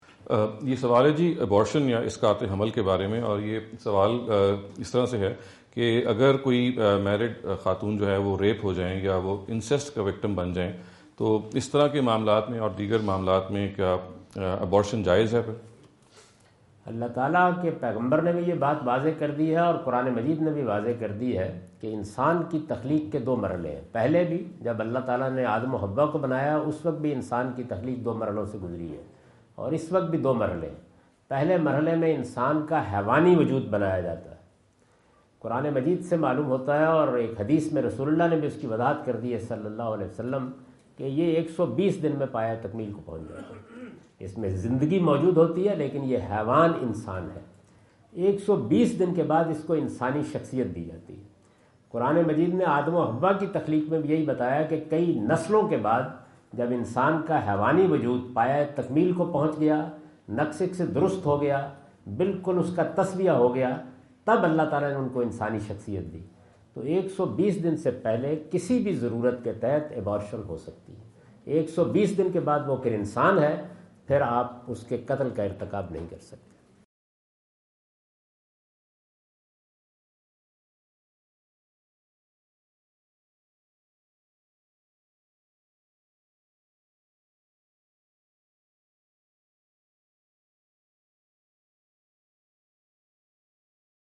Category: English Subtitled / Questions_Answers /
Javed Ahmad Ghamidi answer the question about "Living in Non-Muslim Countries" asked at Corona (Los Angeles) on October 22,2017.
جاوید احمد غامدی اپنے دورہ امریکہ 2017 کے دوران کورونا (لاس اینجلس) میں "غیر مسلم ممالک میں رہنا" سے متعلق ایک سوال کا جواب دے رہے ہیں۔